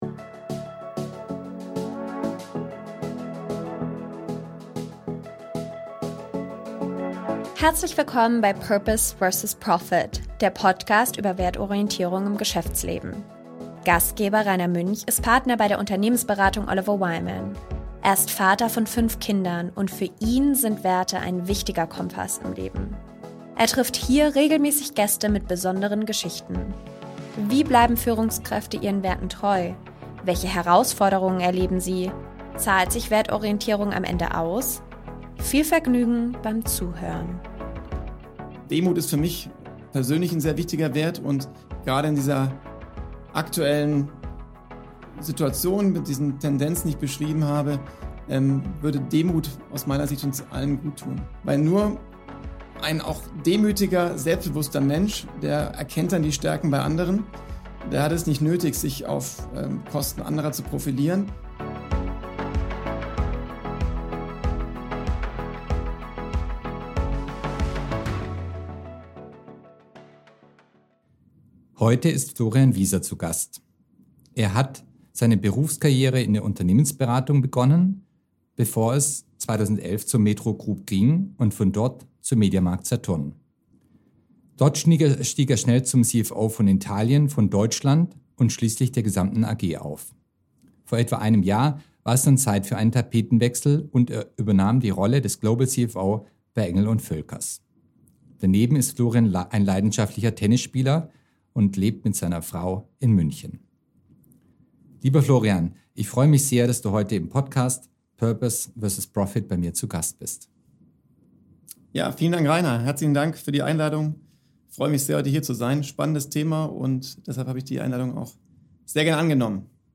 Das Gespräch widmet sich Themen wie Konsumstreben, zunehmender Polarisierung und verstärkter Egozentrik in der Gesellschaft aber auch der zunehmenden Selbstpositionierung von Führungskräften auf LinkedIn, die an vielen Stellen kein Paradebeispiel für Demut ist.